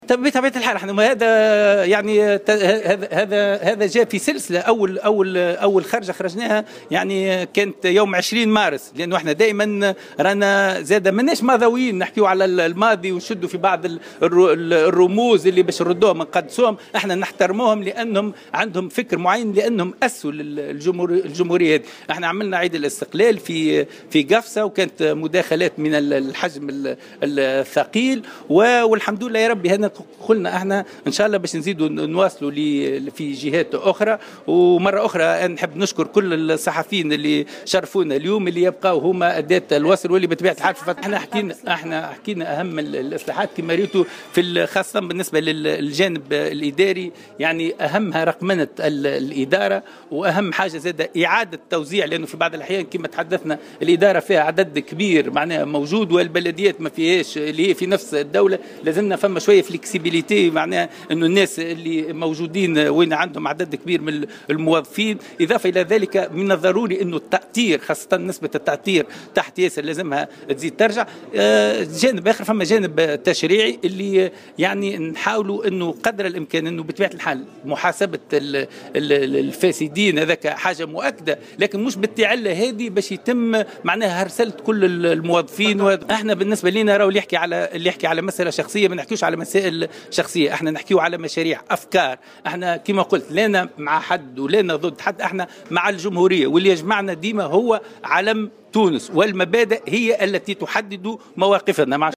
وأكد رئيس "منتدى الجمهورية" عادل الخبثاني، والذي شغل سابقا خطة والٍ على المنستير وصفاقس، في تصريح للجوهرة أف أم، أن عملية الإصلاح لا بد أن تنطلق عبر المصالحة الوطنية والكف عن شيطنة التونسيين بعضهم بعضا.